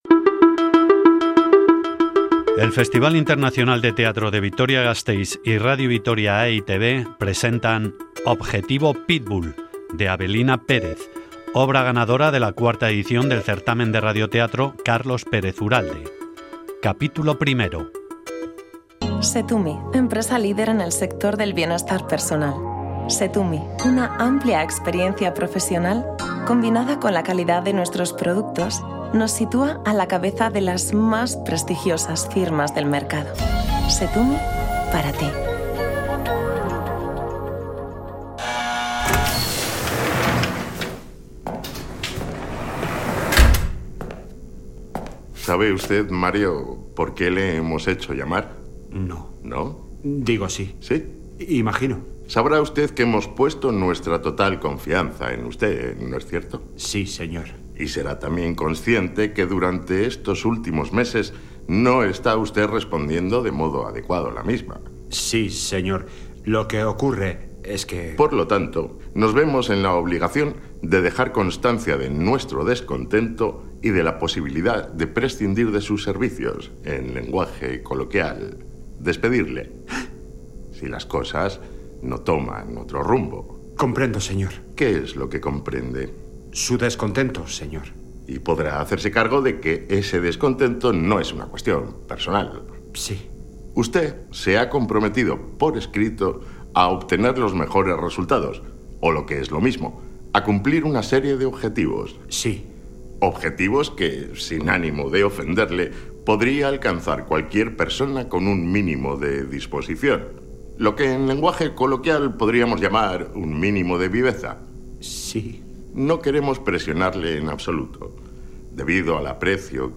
Radionovela
Grabado en Sonora Estudios.